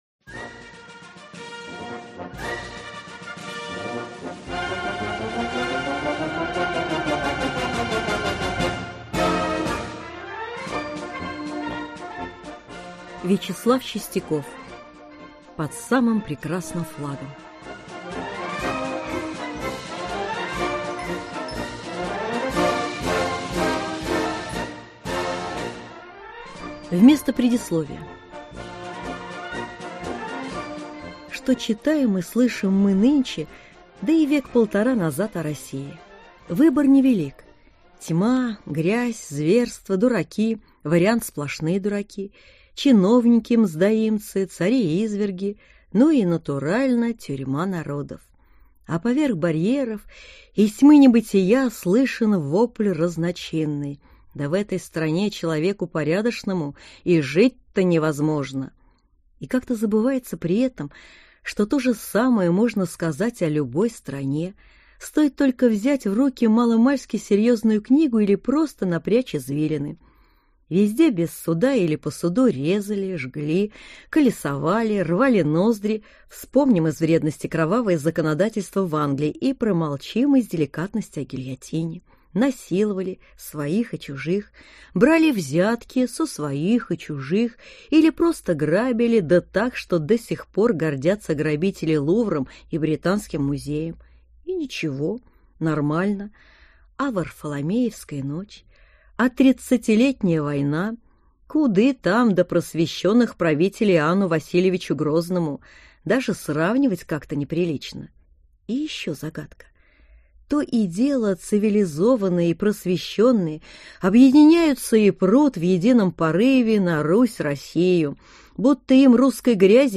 Аудиокнига Под самым прекрасным флагом | Библиотека аудиокниг
Прослушать и бесплатно скачать фрагмент аудиокниги